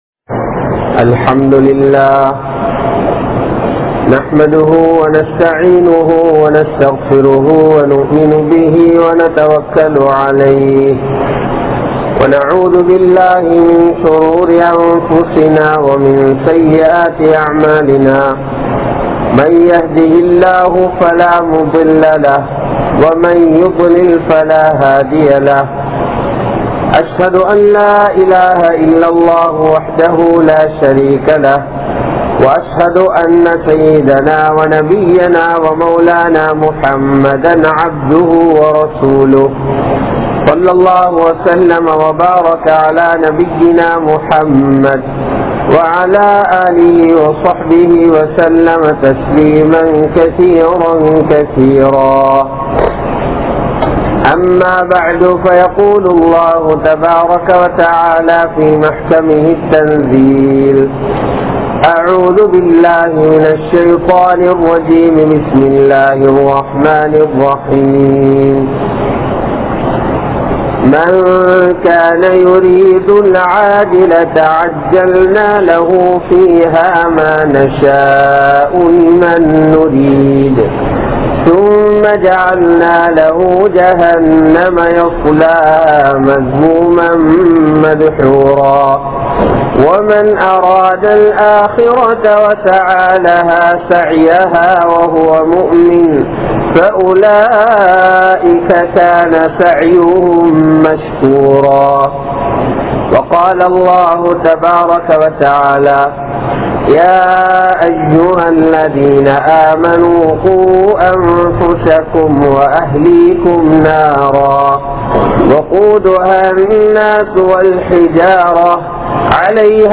Marumaiyai Maranthavarhal (மறுமையை மறந்தவர்கள்) | Audio Bayans | All Ceylon Muslim Youth Community | Addalaichenai